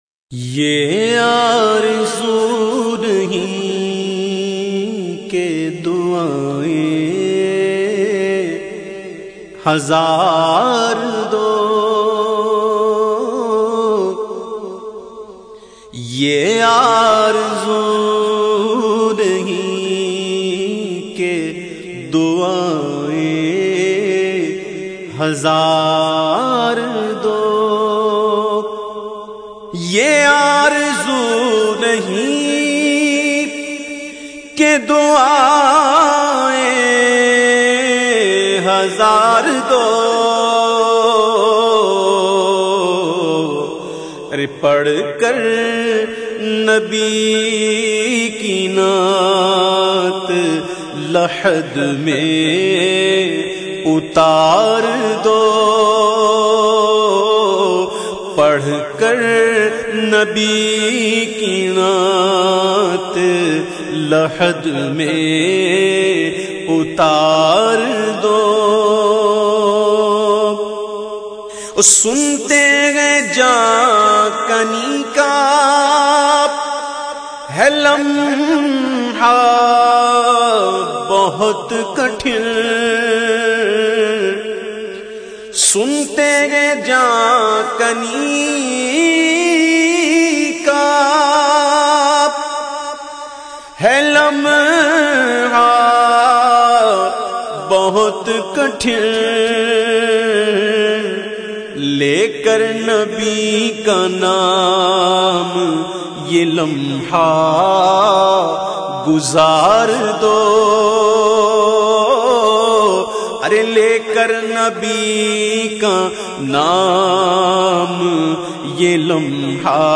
Naat Sharif